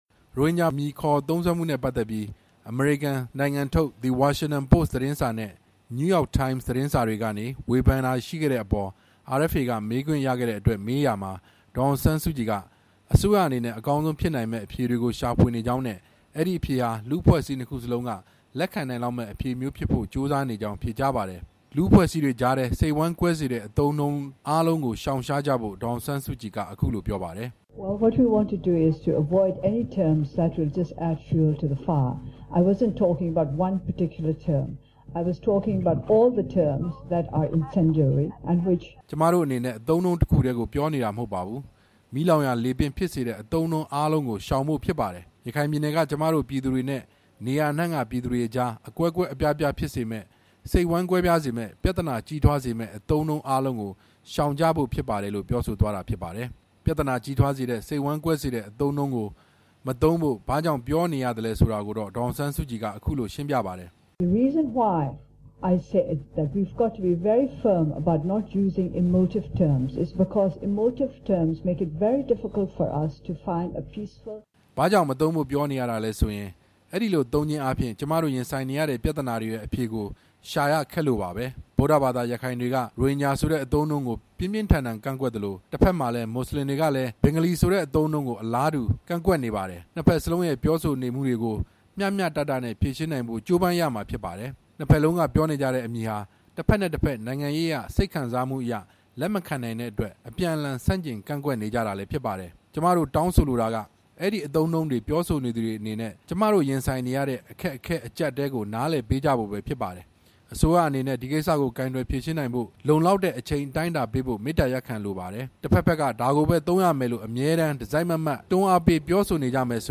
အမေရိကန်နိုင်ငံခြားရေးဝန်ကြီး John Kerry နဲ့ နေပြည်တော် နိုင်ငံခြားရေးဝန်ကြီးရုံးမှာ ကျင်းပတဲ့ ပူးတွဲသတင်း စာရှင်းလင်းပွဲမှာ RFA က ရိုဟင်ဂျာကိစ္စနဲ့ ပတ်သက်ပြီး အမေရိကန်သတင်းစာတွေက ဝေဖန်ရေးသားနေတဲ့ အပေါ် မှတ်ချက်ပြုပေးဖို့ မေးမြန်းရာမှာ ဒေါ်အောင်ဆန်းစုကြည်က ဖြေကြားခဲ့တာ ဖြစ်ပါတယ်။